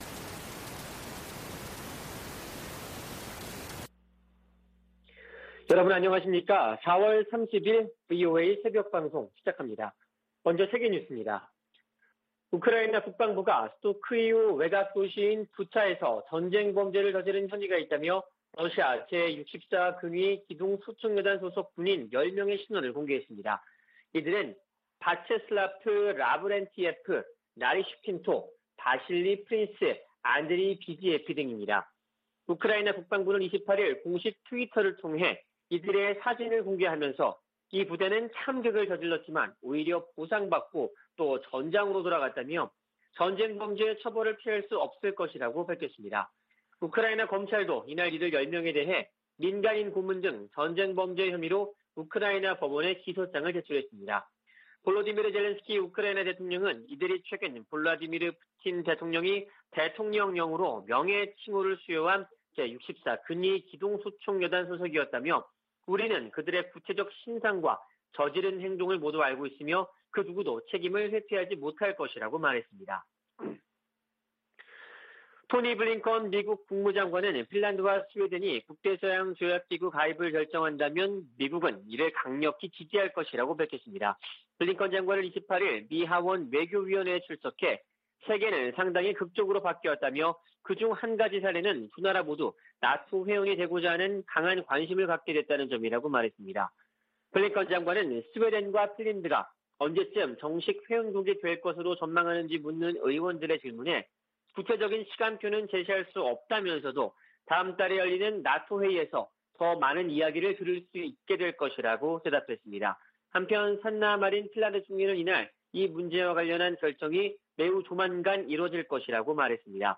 VOA 한국어 '출발 뉴스 쇼', 2022년 4월 30일 방송입니다. 다음 달 미한 정상회담에서는 동맹강화와 대북공조 등이 중점 논의될 것으로 보입니다.